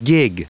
Prononciation du mot gig en anglais (fichier audio)
Prononciation du mot : gig